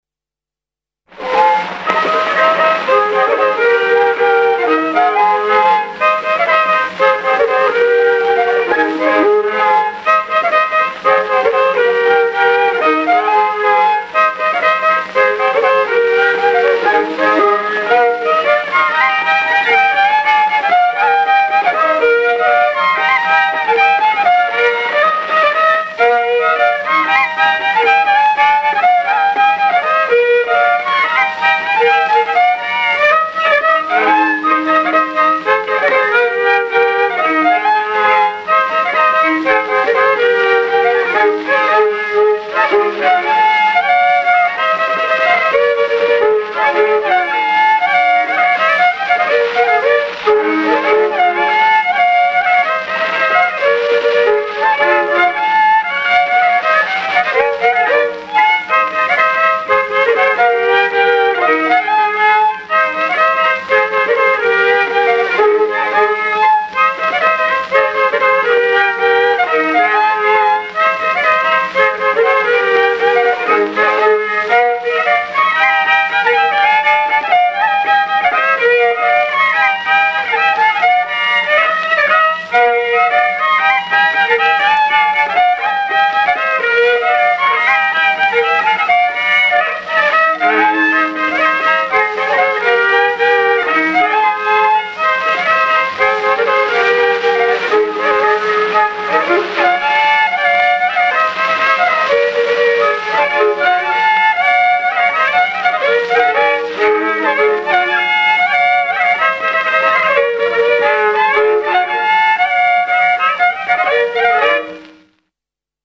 I viiul
II viiul
16 Luige polka.mp3